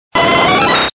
Cri de Voltali dans Pokémon Diamant et Perle.